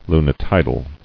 [lu·ni·ti·dal]